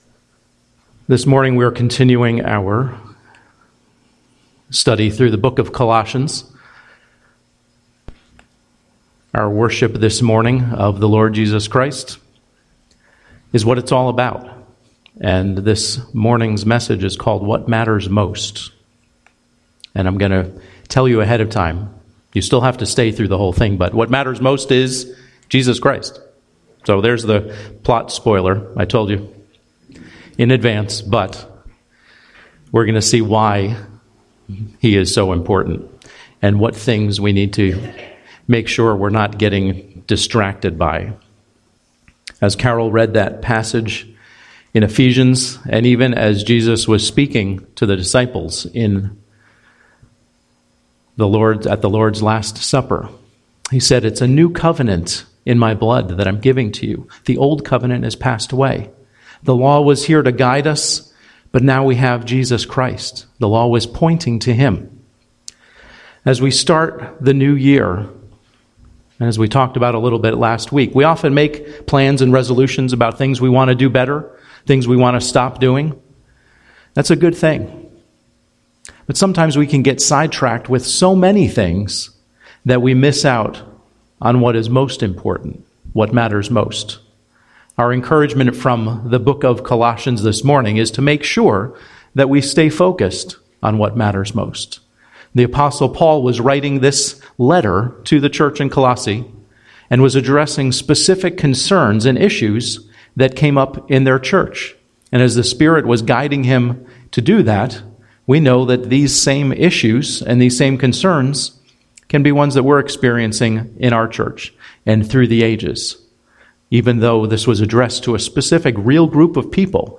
Home › Sermons › What Matters Most